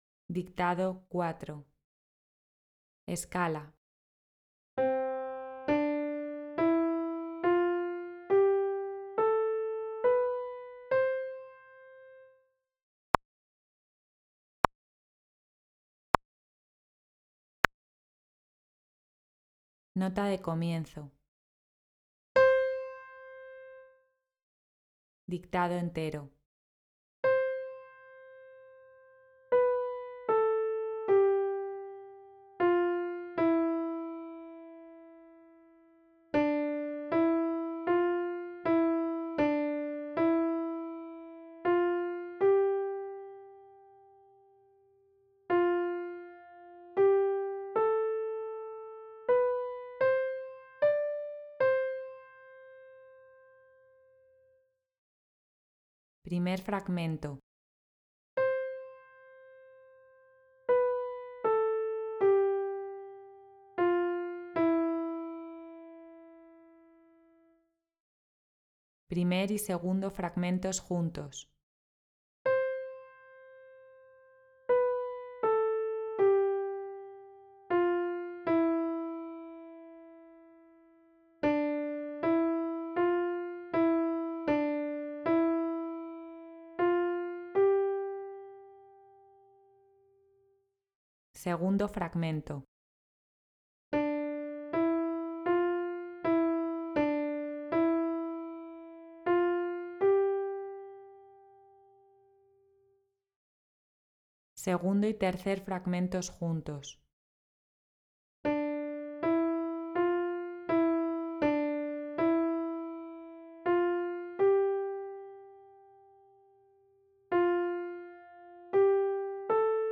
A continuación vas a encontrar un ejercicio de dictado musical en PDF para que te lo descargues y puedas resolverlo con su audio correspondiente.
Primero escucharás la escala correspondiente al ejercicio y a continuación la nota de comienzo y el dictado entero. El ejercicio se dictará por fragmentos y al finalizar escucharemos de nuevo el dictado entero para comprobar el resultado final de nuestra escritura.